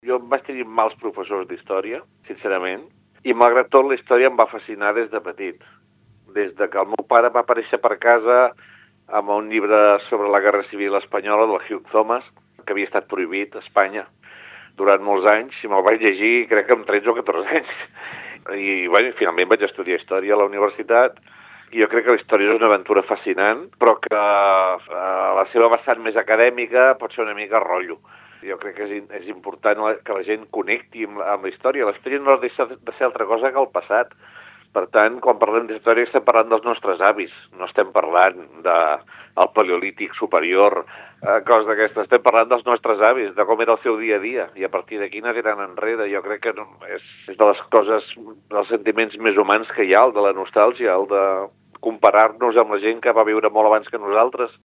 Toni Soler, monologuista de ‘Per què Catalunya no és una potència mundial?’